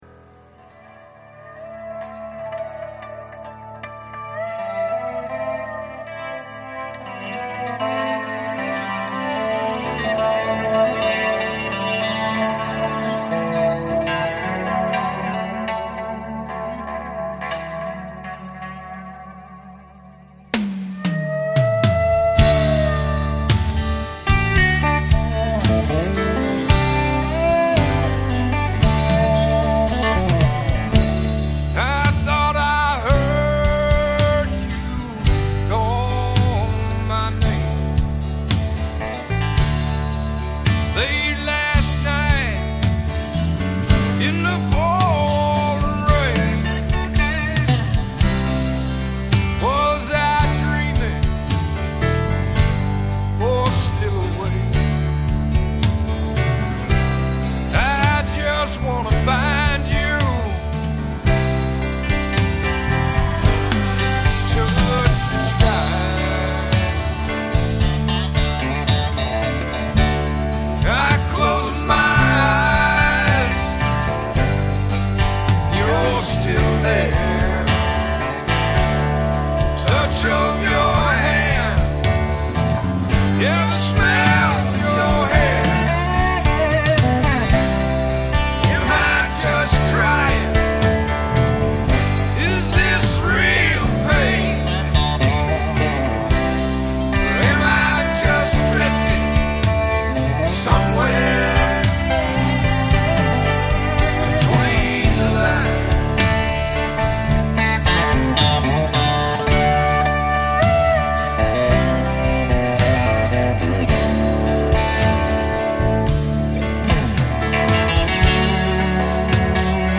RECORDED at Arcade Digital Studios, Tumwater, WA
Guitars
Keyboards
Drums